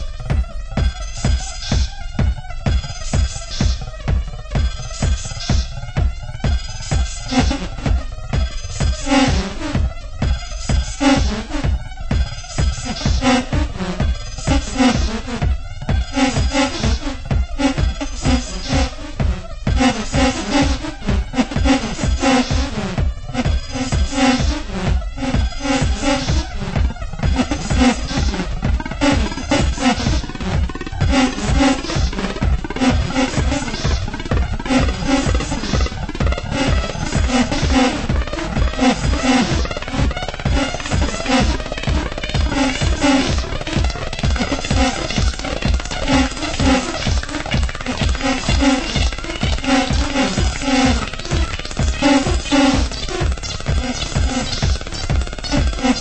○シカゴハウス/テクノをベースにユニークなサウンドメイクのエレクトロ・ファンク！